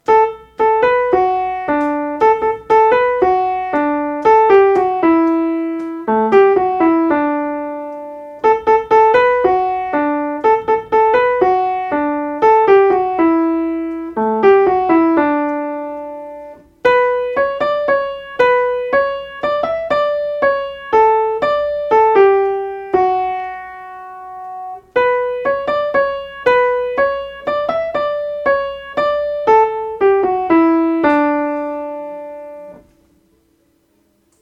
La_colline_aux_corallines_soprane.mp3